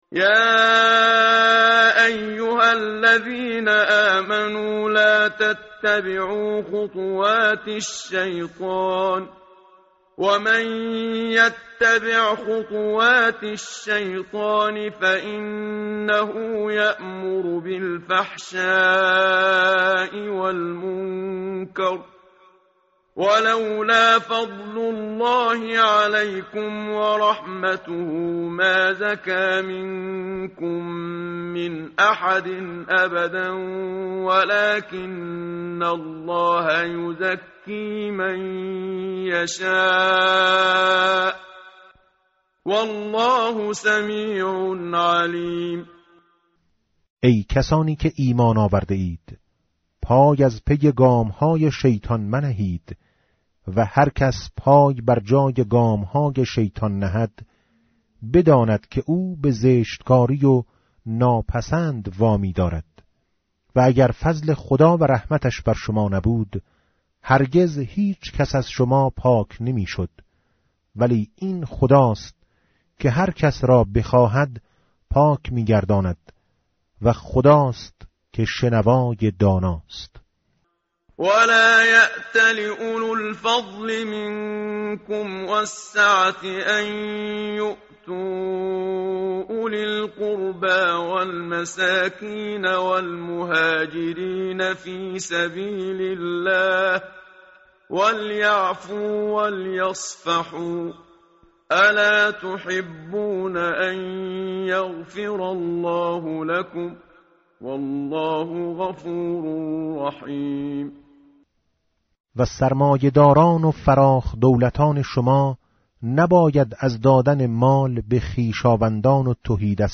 متن قرآن همراه باتلاوت قرآن و ترجمه
tartil_menshavi va tarjome_Page_352.mp3